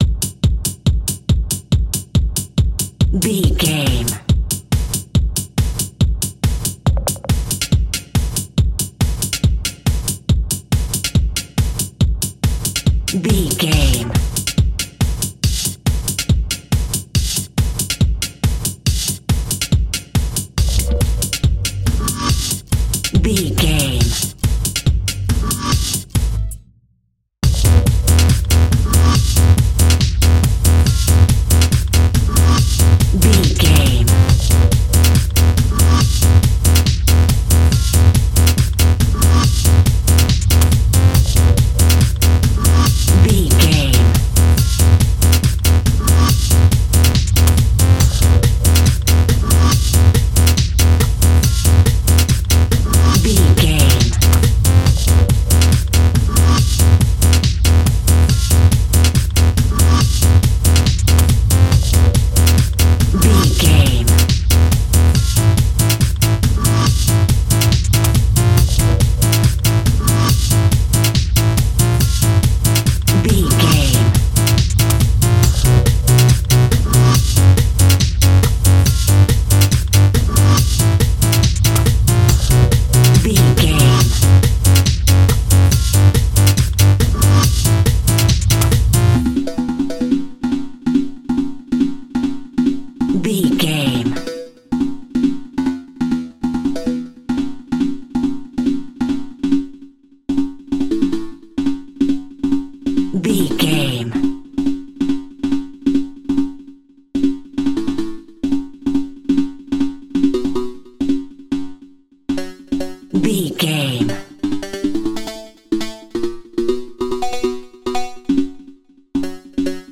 Epic / Action
Fast paced
In-crescendo
Aeolian/Minor
dark
futuristic
driving
energetic
tension
synthesiser
drum machine
techno
electro house